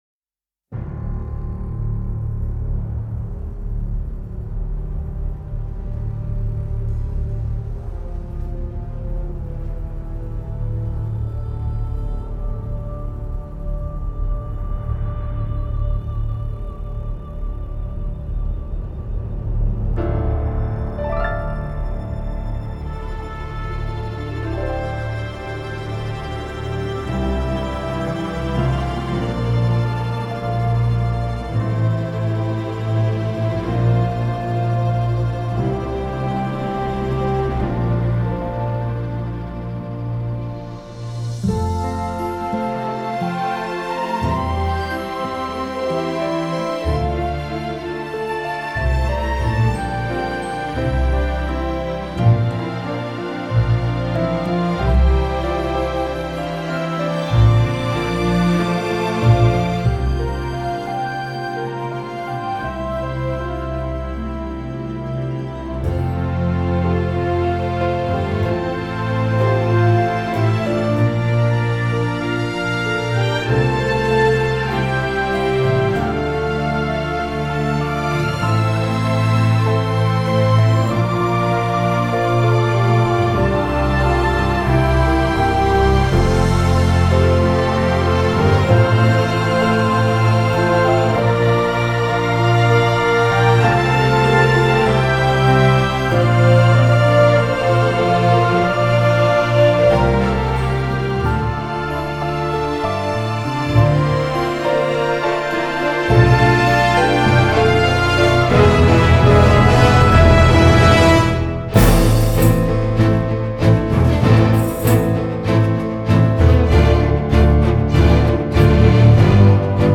This is the extended version, not found on the album.